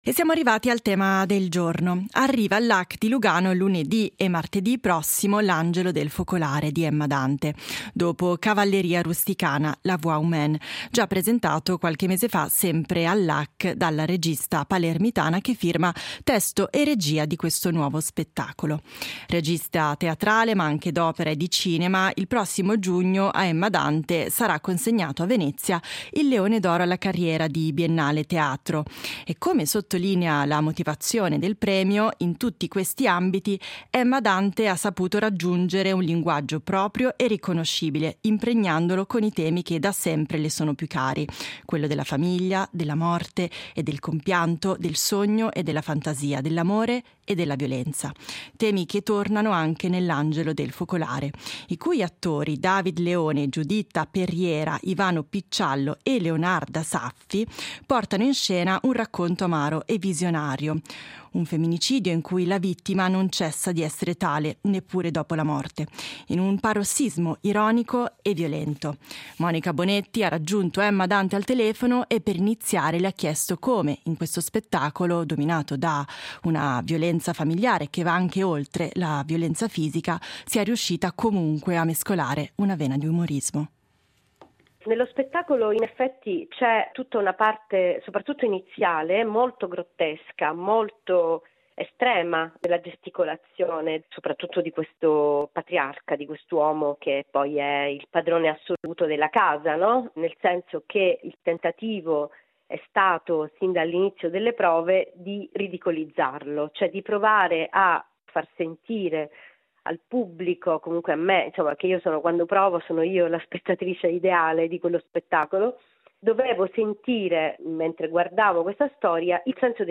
ha raggiunto al telefono Emma Dante